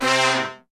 C3 POP FAL.wav